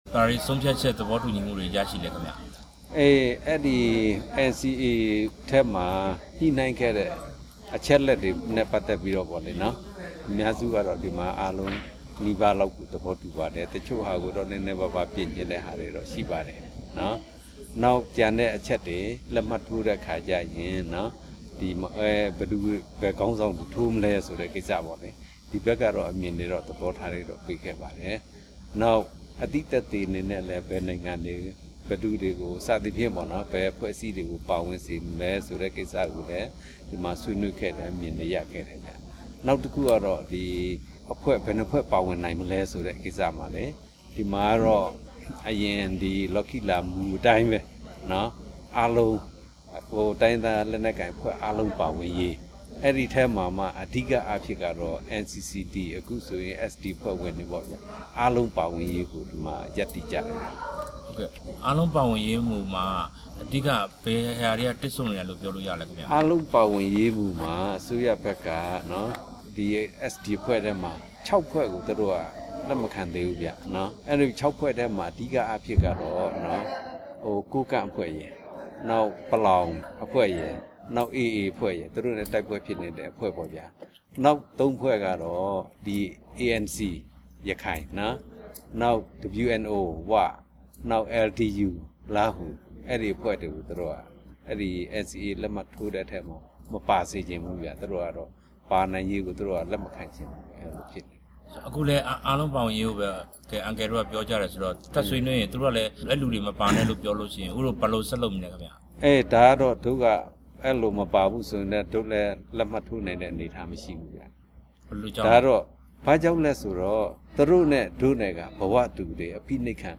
တွေ့ဆုံမေးမြန်းထားပါတယ်။